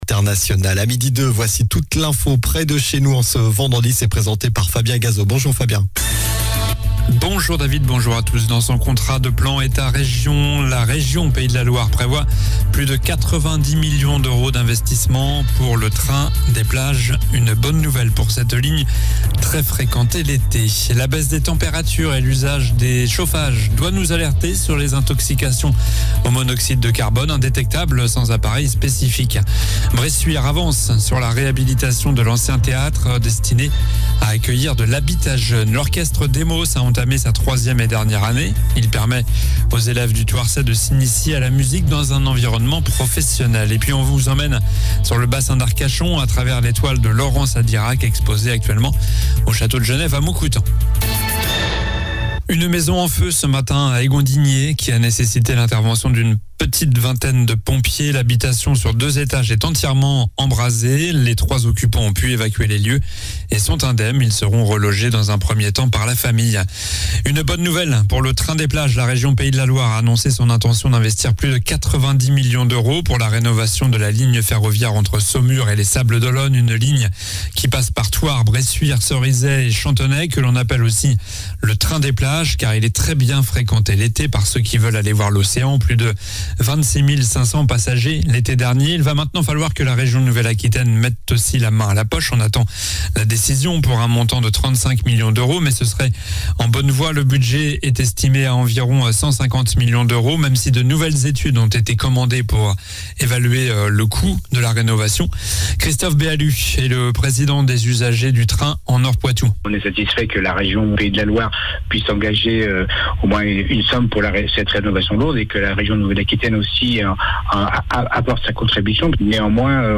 Journal du vendredi 24 novembre (midi)